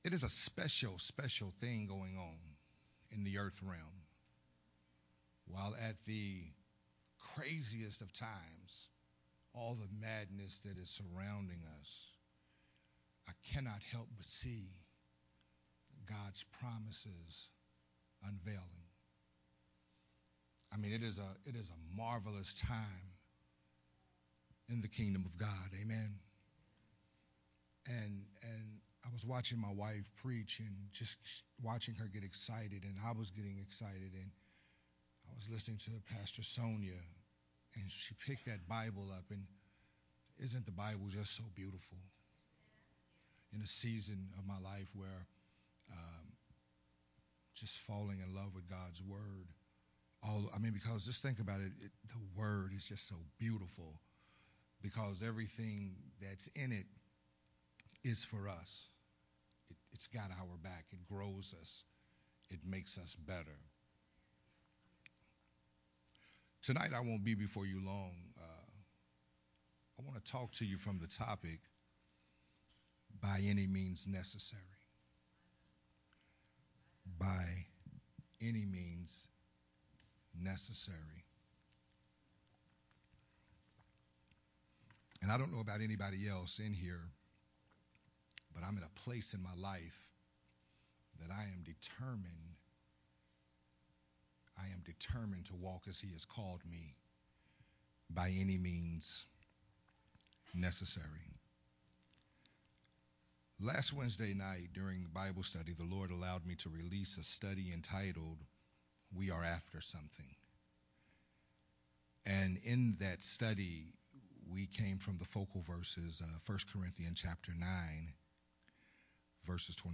a New Year’s Eve Sermon
and recorded at Unity Worship Center on December 31st, 2022.